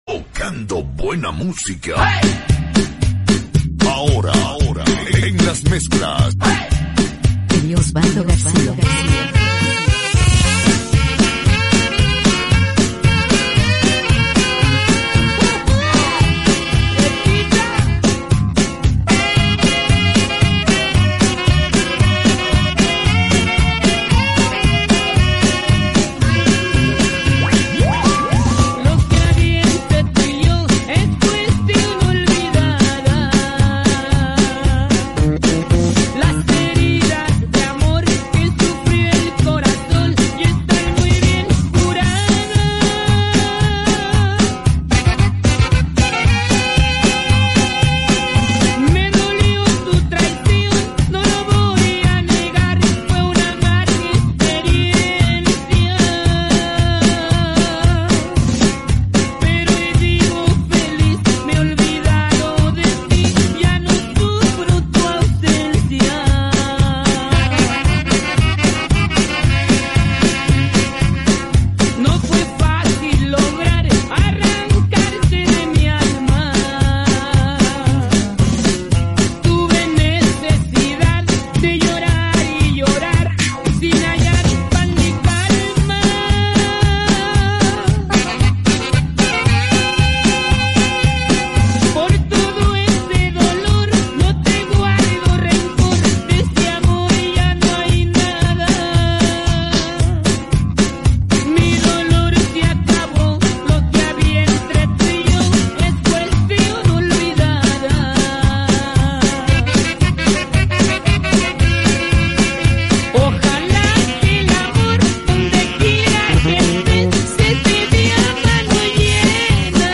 Mixes y Remixes